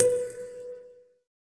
SYN_Pizz3.wav